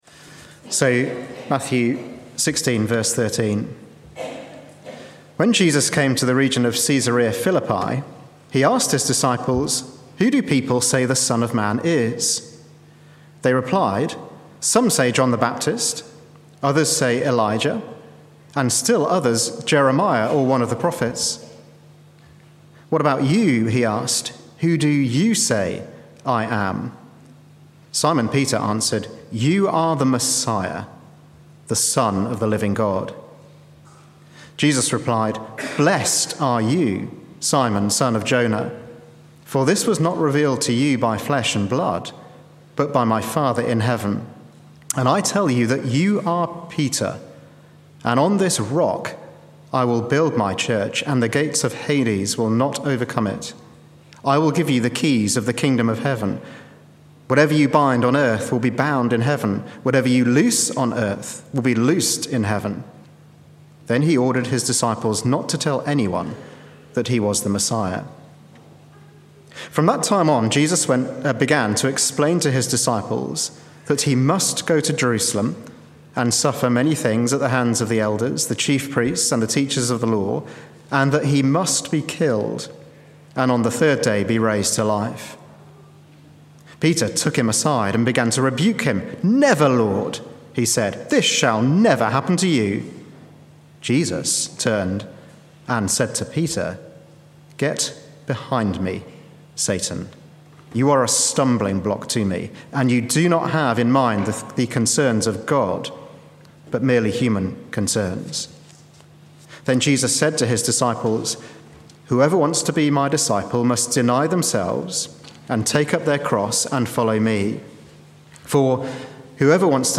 Sermons Archive - Page 23 of 187 - All Saints Preston